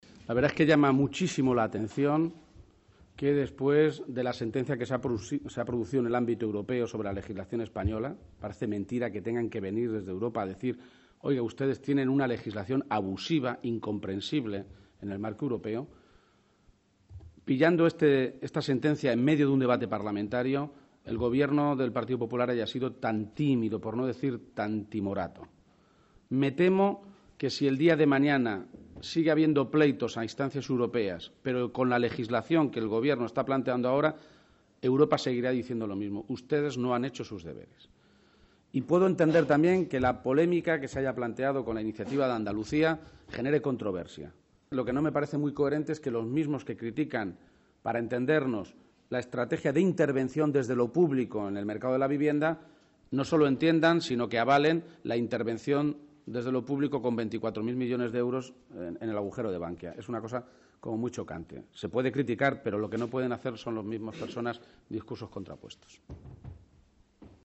García-Page se pronunciaba de esta manera en una rueda de prensa conjunta, en Valencia, junto al secretario general del PSOE valenciano, en la capital de la comunidad vecina.
Cortes de audio de la rueda de prensa